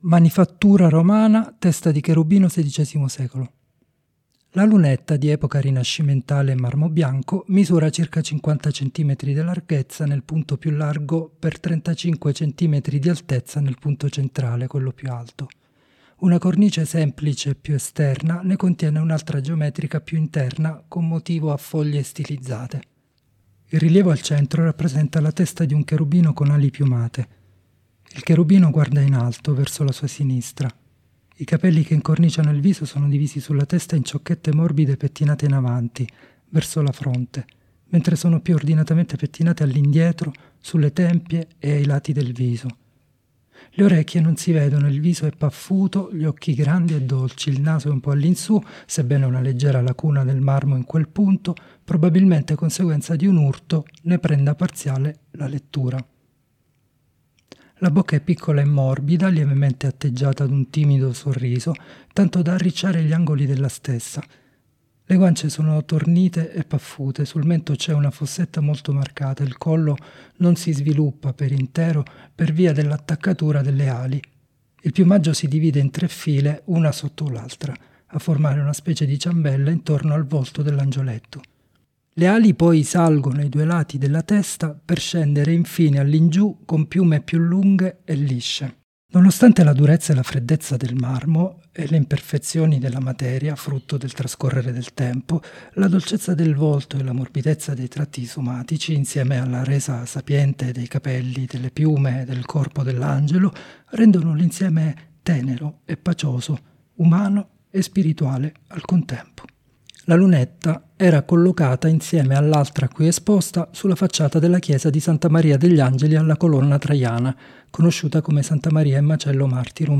Audiodescrizioni sensoriali opere selezionate: